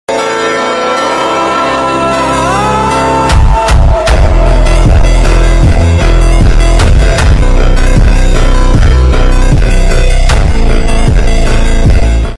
Big Planes At Corfu 🗣🗣yes Sound Effects Free Download